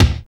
SWING BD 3.wav